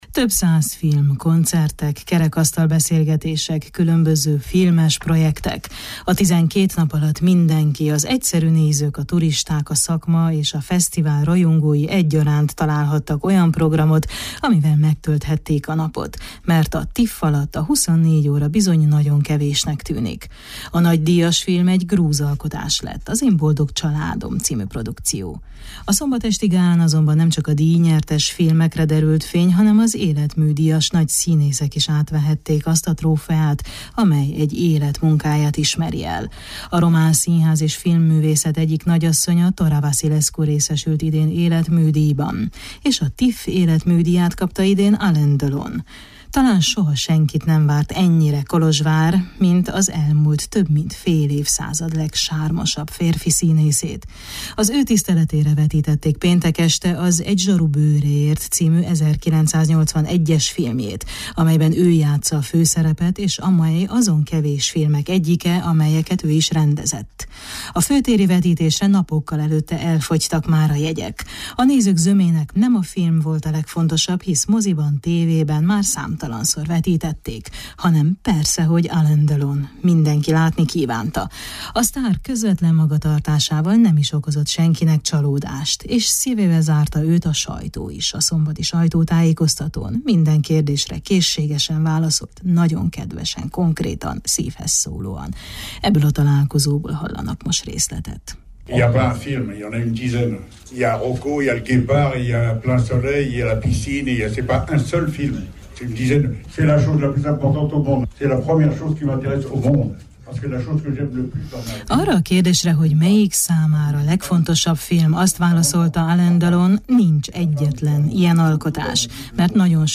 A sajtóval szombat délelőtt találkozott szintén hatalmas biztonsági intézkedések közepette. Az újságírók filmekkel és természetesen a nőkkel kapcsolatban kérdezték elsősorban. Az alábbi összeállításban részleteket hallanak a találkozóból.